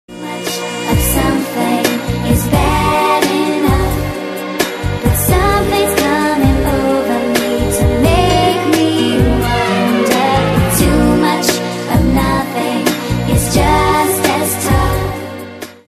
Spanish Guitar